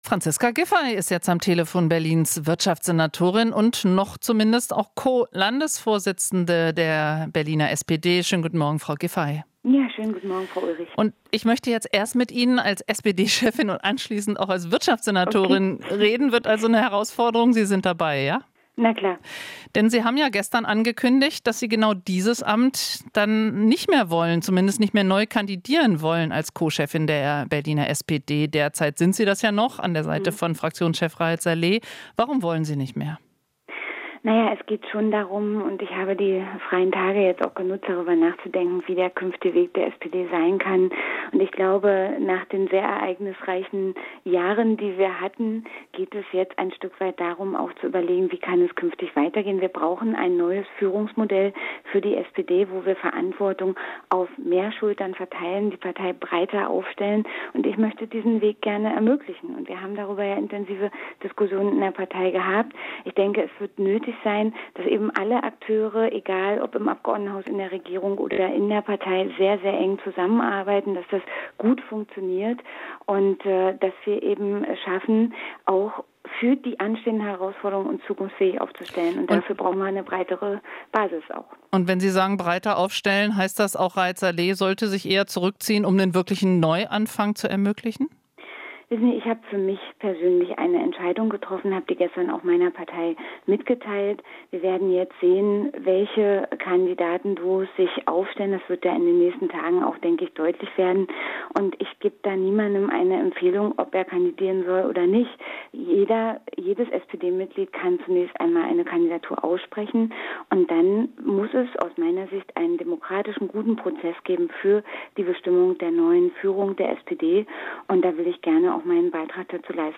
Interview - Giffey: "Brauchen ein neues Führungsmodell für die SPD"